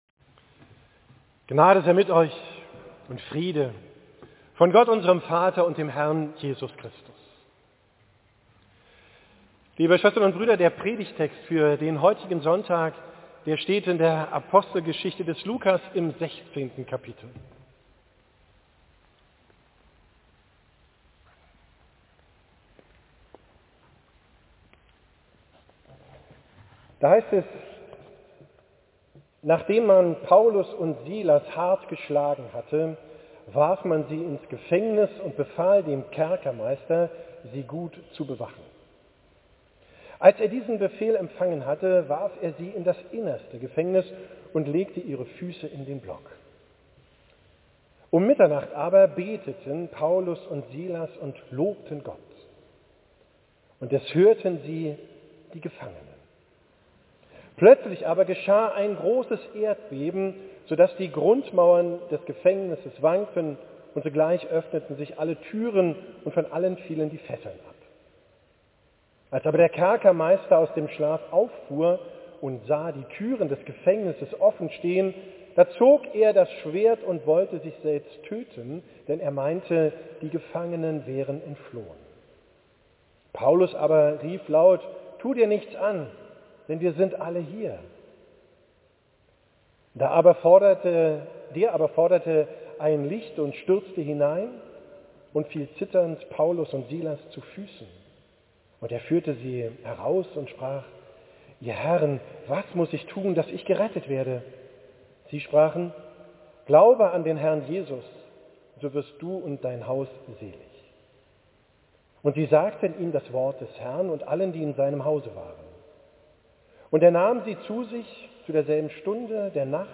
Predigt vom Sonntag Kantate, 18.